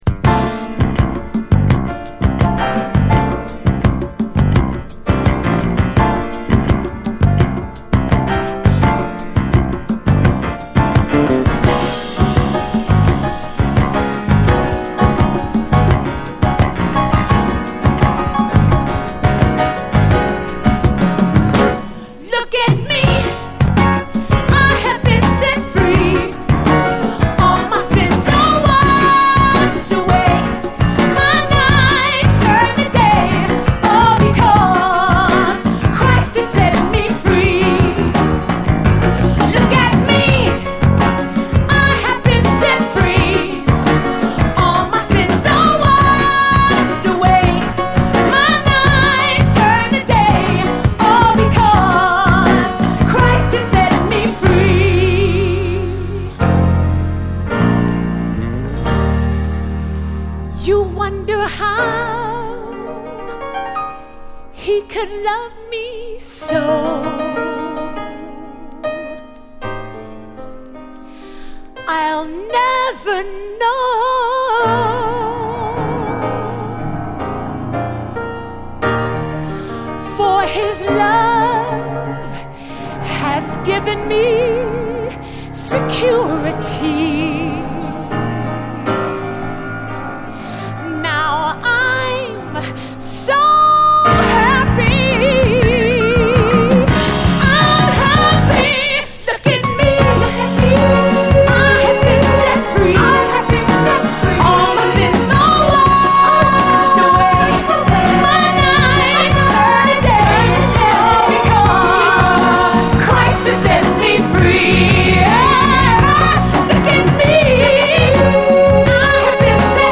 SOUL, 70's～ SOUL
感動的な清らかさの最高ゴスペル・ソウル
感動的な清らかさ、解放感に満ちた最高ゴスペル・ソウル